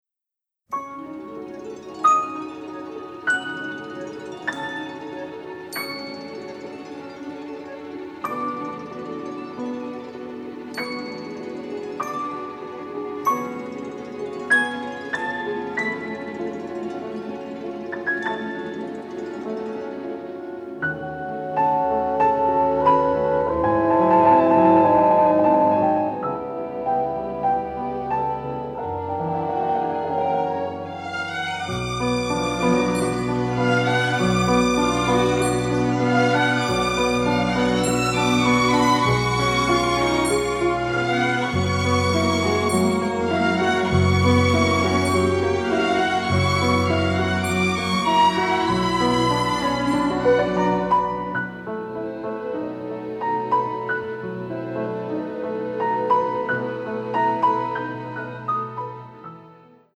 mostly in stereo, with some cues in mono